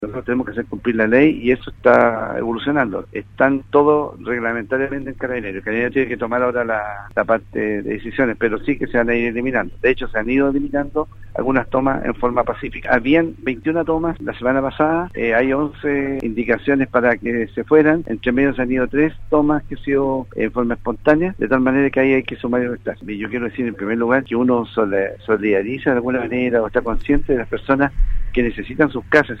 En conversación con Radio Sago, el gobernador provincial de Osorno, Daniel Lilayú, aclaró cómo se mantienen actualmente los respectivos desalojos de las tomas de terrenos en la ciudad.